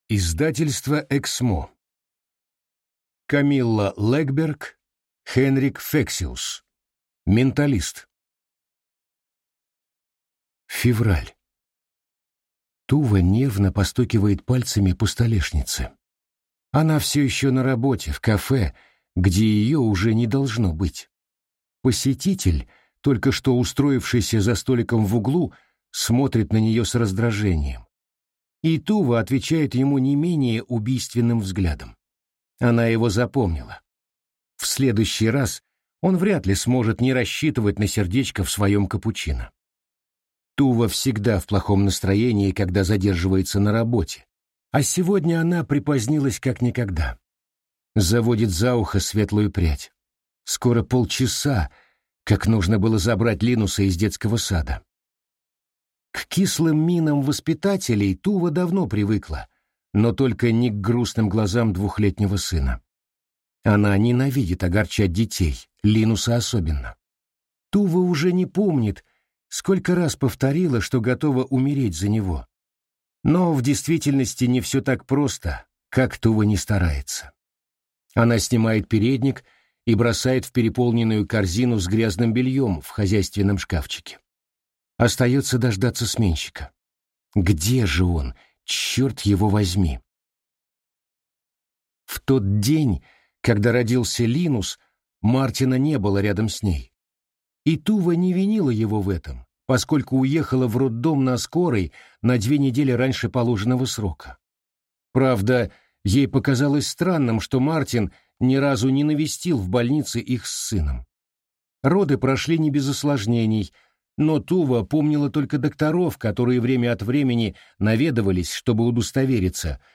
Аудиокнига Менталист | Библиотека аудиокниг